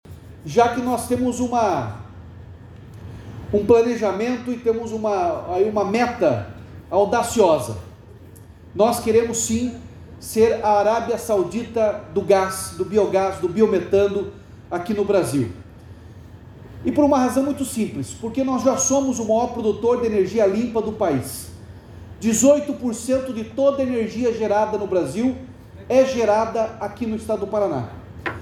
Durante a cerimônia, foi anunciada ainda a formalização do primeiro contrato da gestão do gás biometano no estado. A iniciativa tem como objetivo gerar emprego e auxiliar no crescimento econômico do Paraná, como explicando governador Ratinho Junior (PSD).